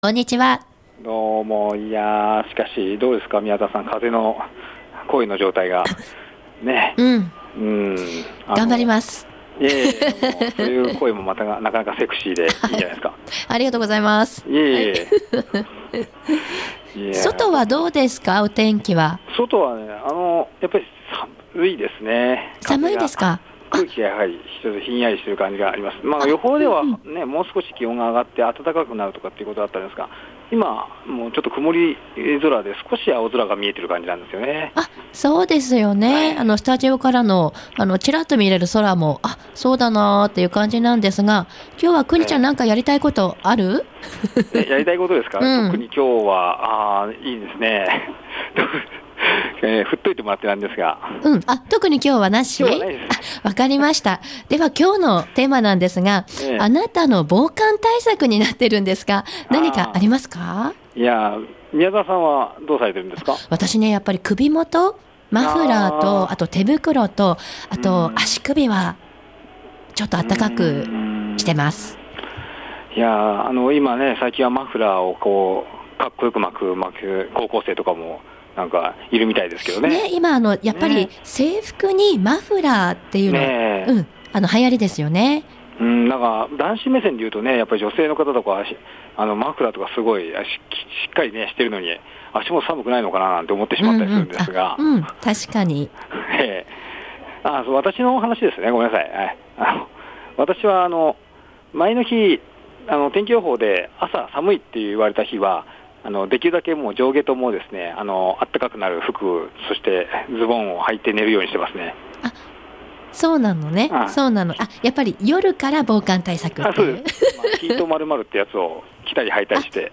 今日は調布の皆様おなじみの真光書店さんにお邪魔しました。